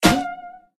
wood_damage_02.ogg